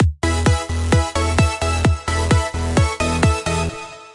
130 BPM EDM循环
描述：所有声音（减去底鼓）都是使用Spire创建的，并使用第三方插件进行处理。
Tag: 循环 音乐 样品 EDM 舞蹈 130-BPM